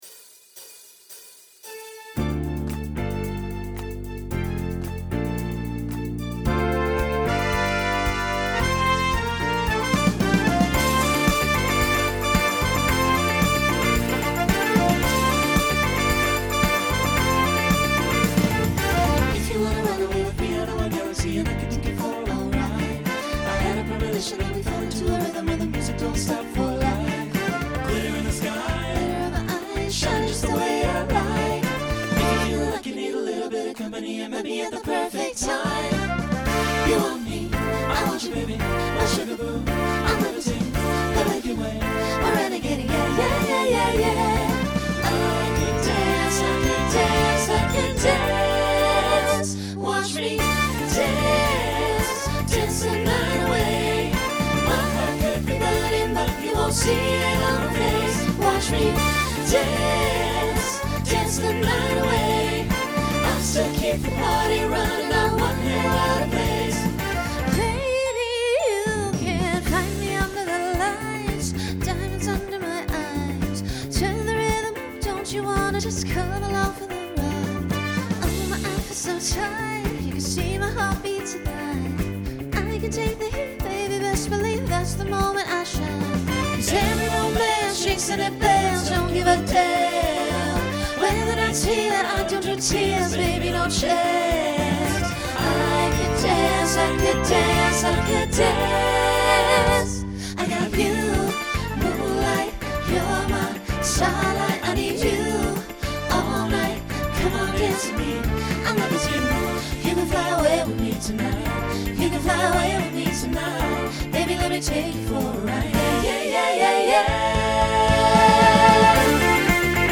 Pop/Dance Instrumental combo
Voicing SATB